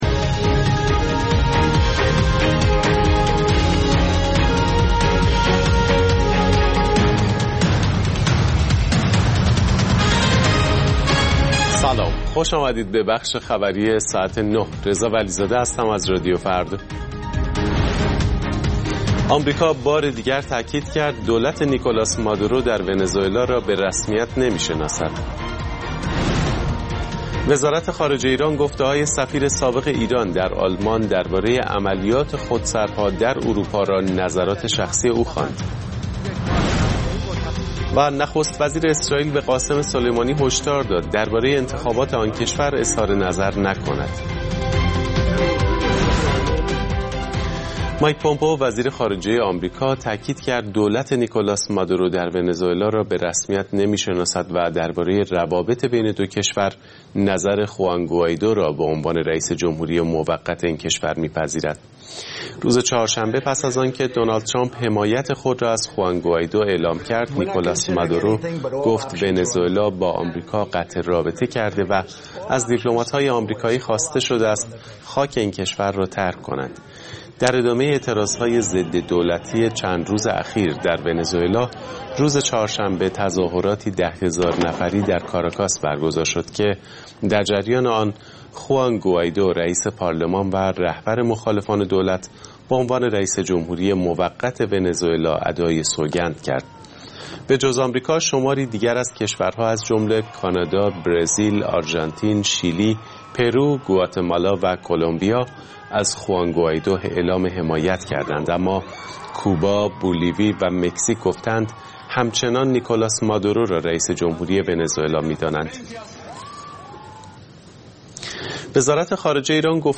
اخبار رادیو فردا، ساعت ۹:۰۰